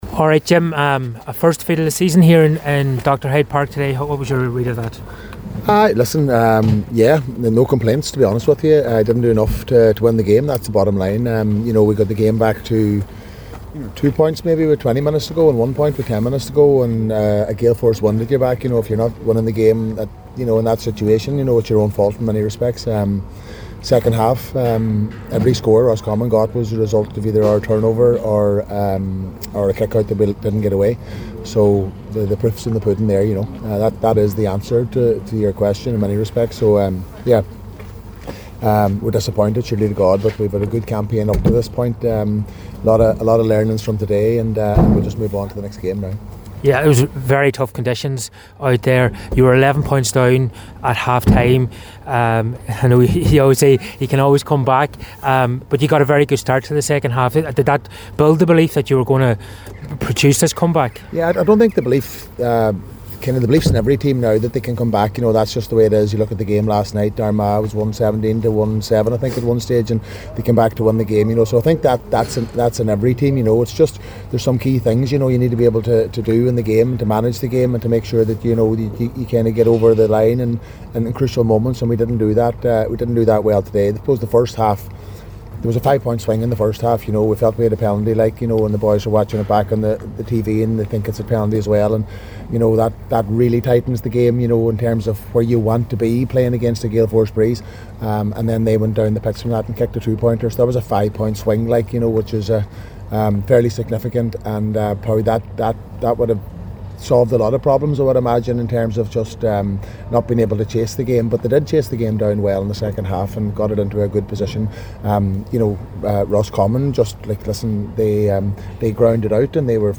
After the game, McGuinness spoke to the assembled media